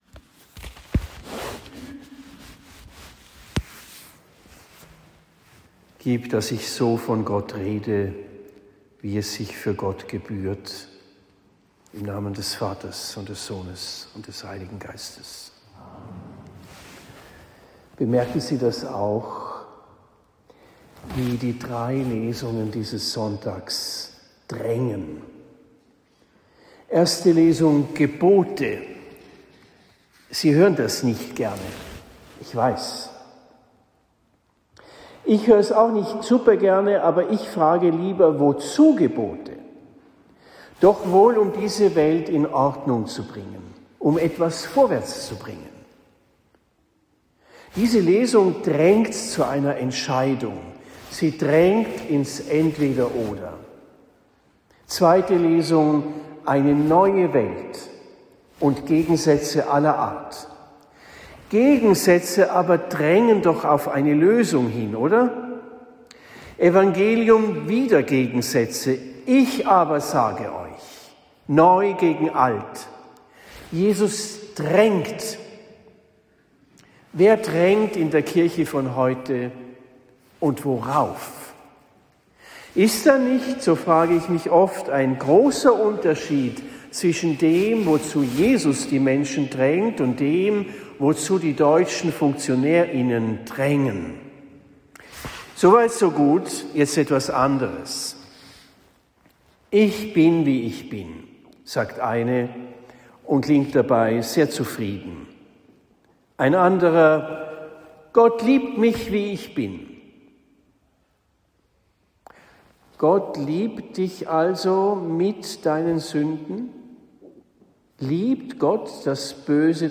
Predigt in Hafenlohr am 14. Februar 2026